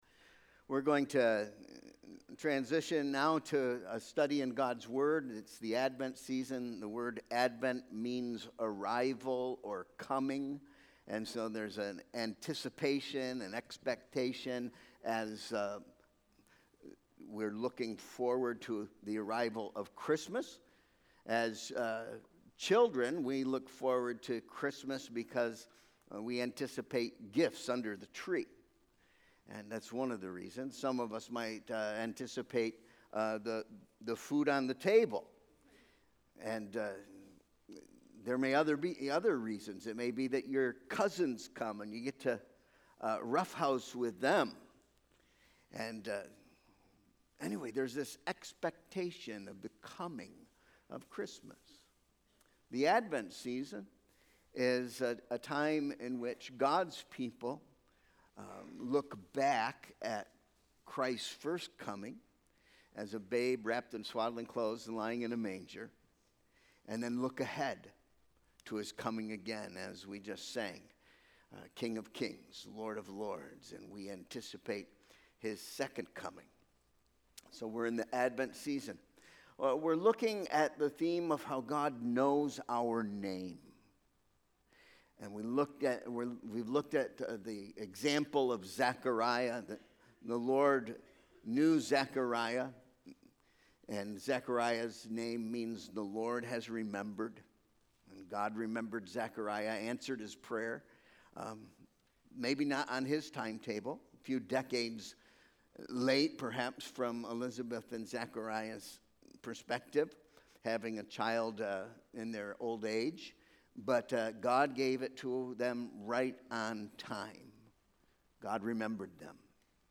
Sermon Proposition: The Hebrew name Joseph means “the Lord has added”. The example of Joseph in this text teaches us that if we seek the Lord, He will add to our lives in 4 ways.